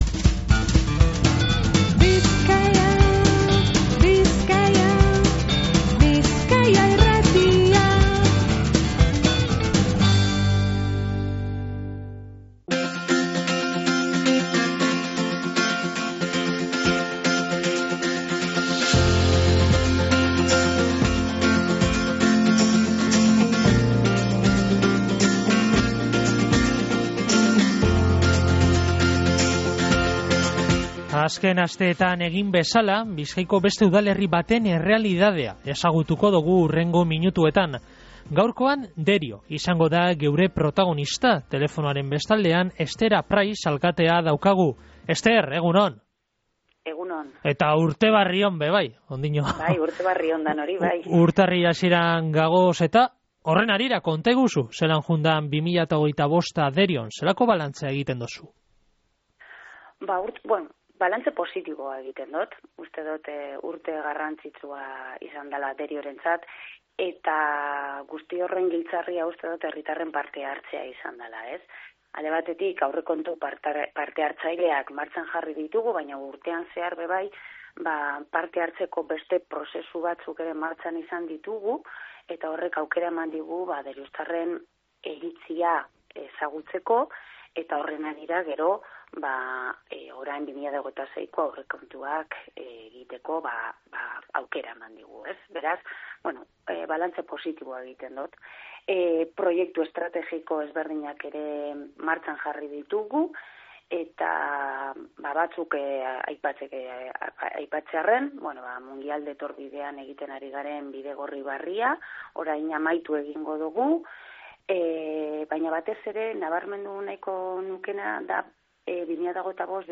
Izan be, Esther Apraiz alkatea euki dogu Bizkaia Irratiko mikrofonoetan. Apraizek 2025eko balantze positiboa egin dau.